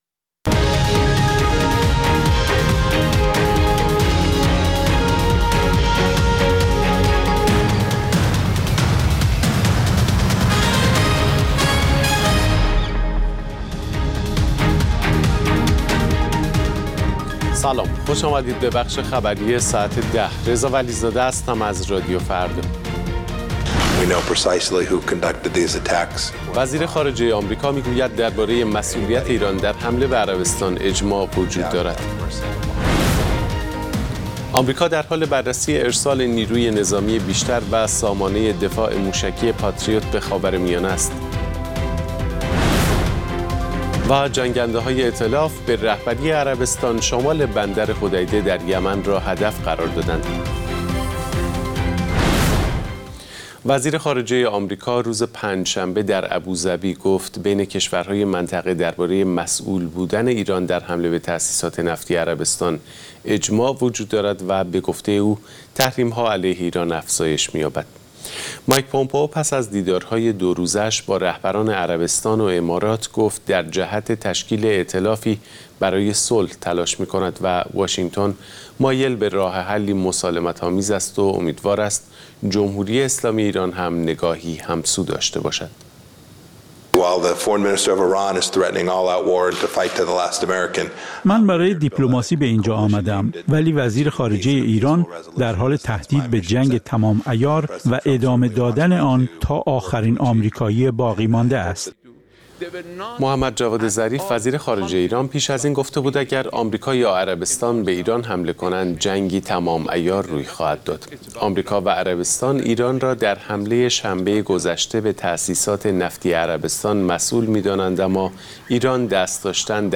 اخبار رادیو فردا، ساعت ۱۰:۰۰